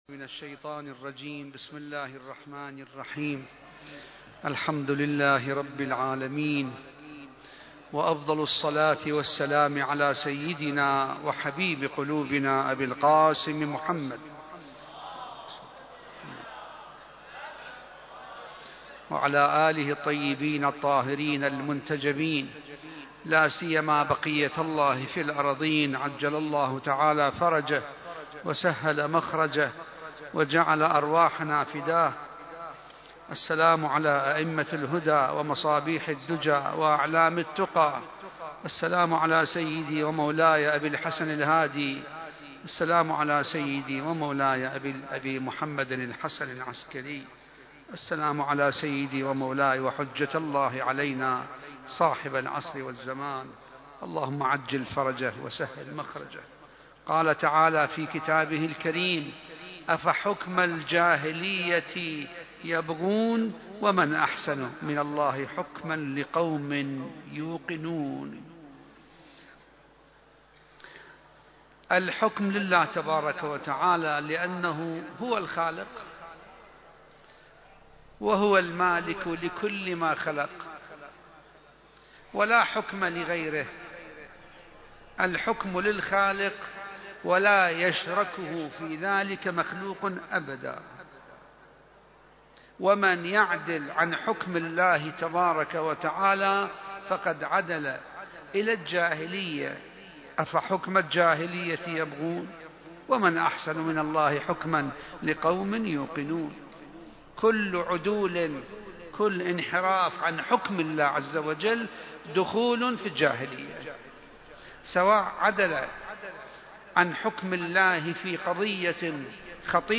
المكان: العتبة العسكرية المقدسة